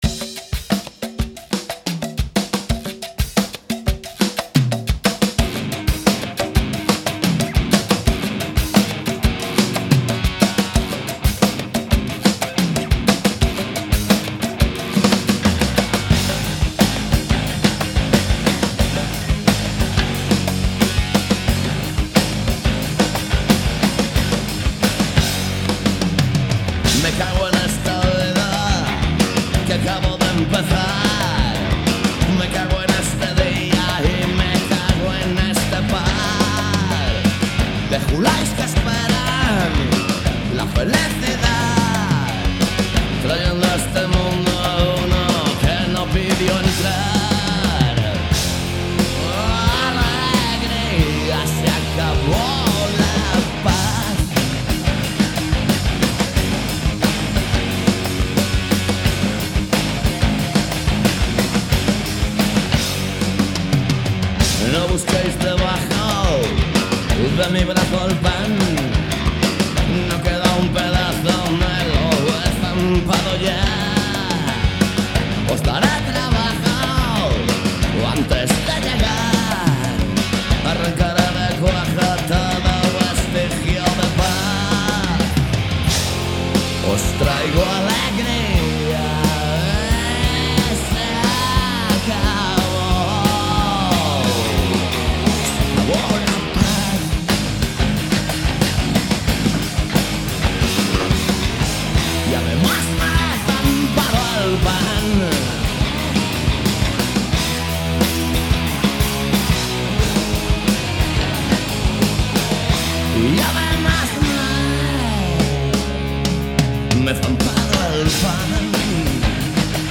Todo iso misturado con boa música e un pouco de humor se o tema o permite. Cada martes ás 18 horas en directo.
Programa emitido cada mércores de 19:00 a 20:00 horas.